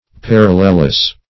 Parallelless \Par"al*lel*less\